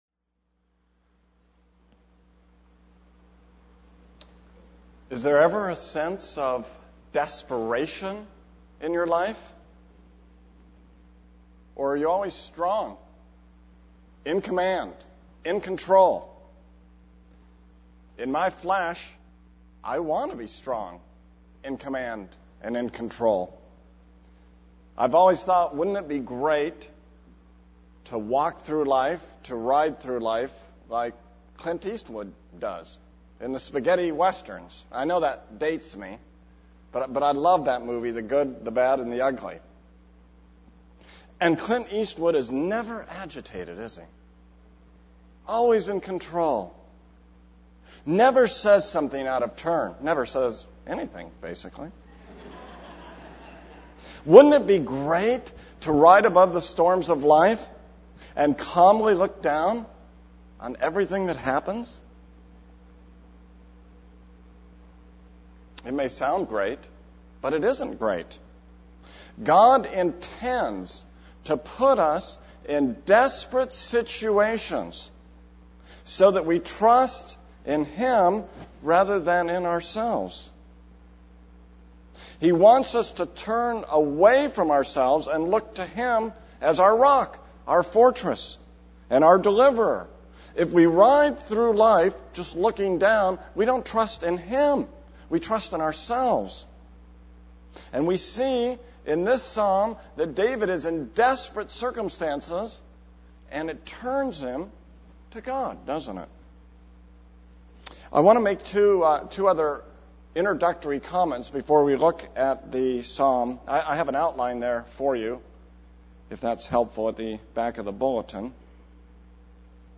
This is a sermon on 2 Samuel 22.